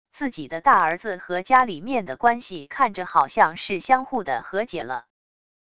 自己的大儿子和家里面的关系看着好像是相互的和解了wav音频生成系统WAV Audio Player